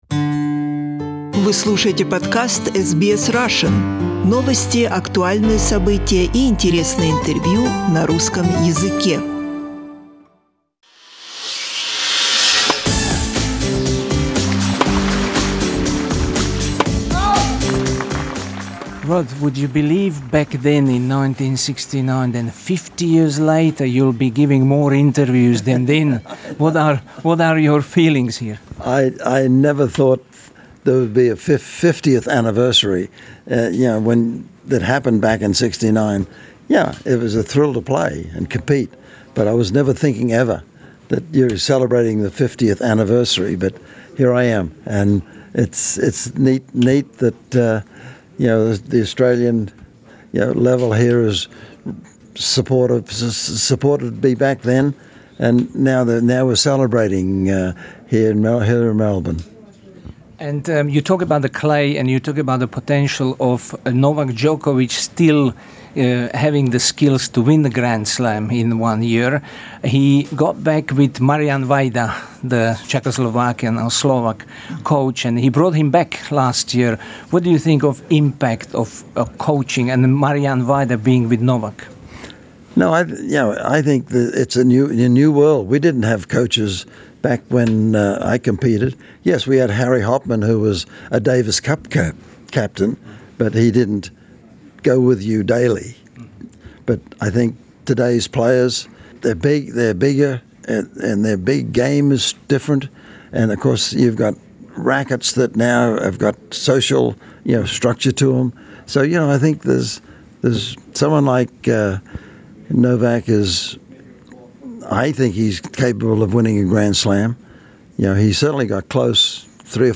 В эксклюзивном интервью радио SBS Род Лейвер говорит, что Джокович может выиграть этот турнир Большого шлема, но он ведет выжидающую игру. Господин Лейвер говорит, что возможности для современных теннисистов в сравнение не идут с теми, что были во времена его собственной теннисной славы.